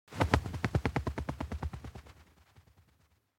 دانلود صدای پرنده 54 از ساعد نیوز با لینک مستقیم و کیفیت بالا
جلوه های صوتی